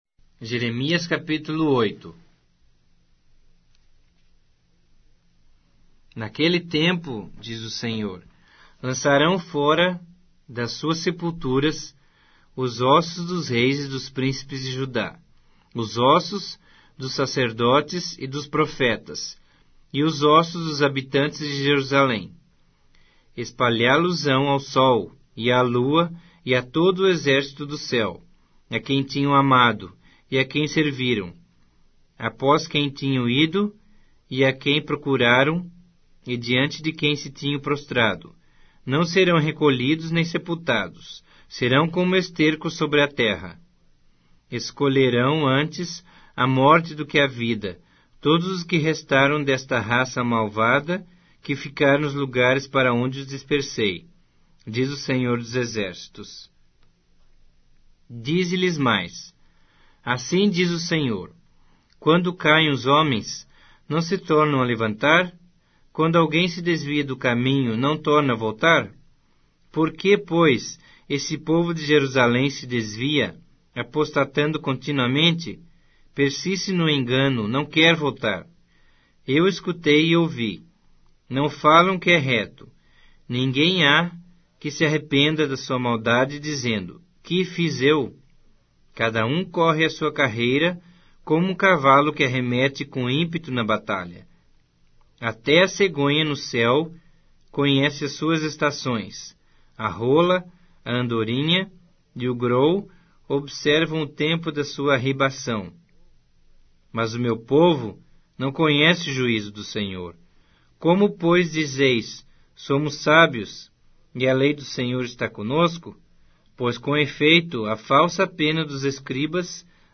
Bíblia Sagrada Online Falada